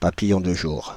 Ääntäminen
Synonyymit papillon diurne rhopalocère Ääntäminen France (Île-de-France): IPA: /pa.pi.jɔ̃ də ʒuʁ/ Haettu sana löytyi näillä lähdekielillä: ranska Käännöksiä ei löytynyt valitulle kohdekielelle.